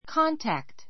kɑ́ntækt